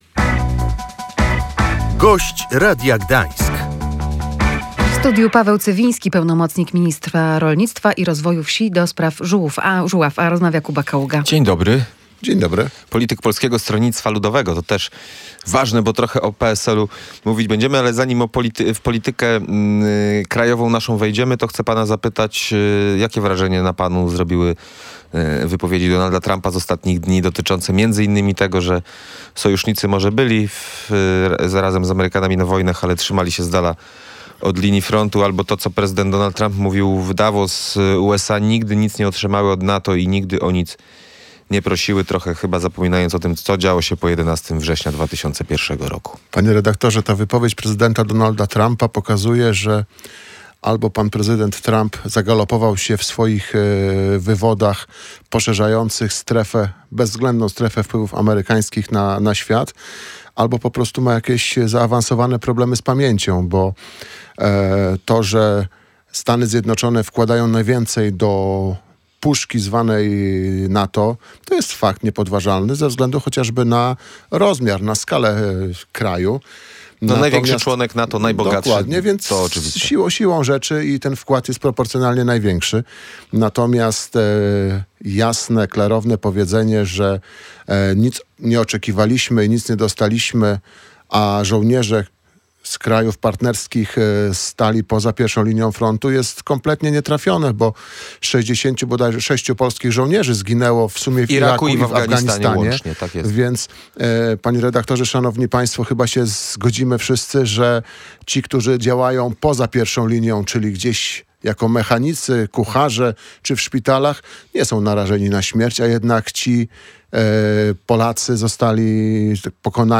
Sposób zawarcia umowy z krajami Mercosur mógł być niezgodny z prawem Unii Europejskiej – mówił w Radiu Gdańsk Paweł Cywiński z Polskiego Stronnictwa Ludowego, pełnomocnik ministra rolnictwa i rozwoju wsi ds. Żuław.